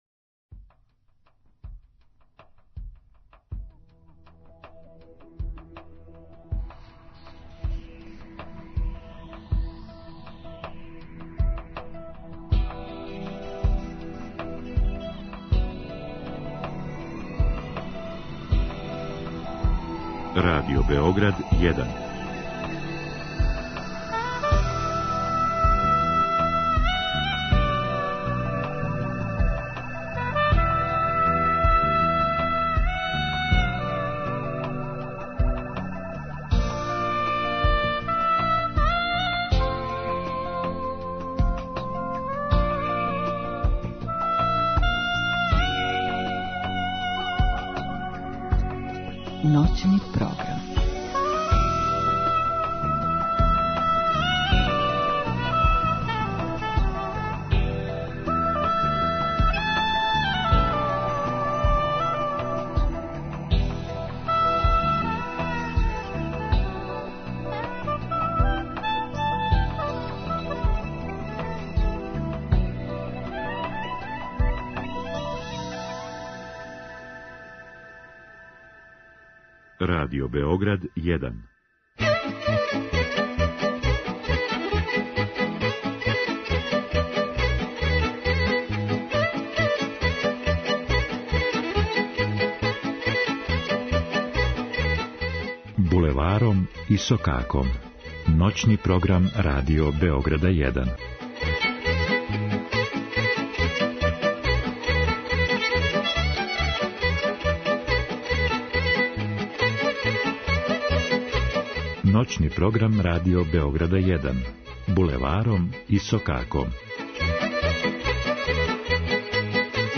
Изворна, староградска и музика у духу традиције.